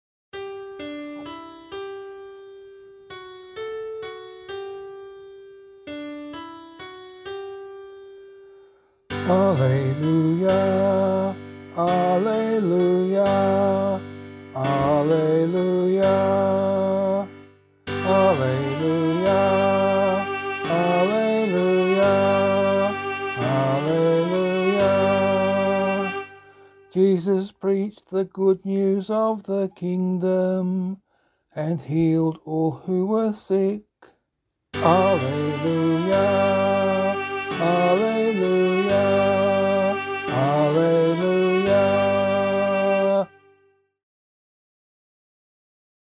Gospel Acclamation for Australian Catholic liturgy.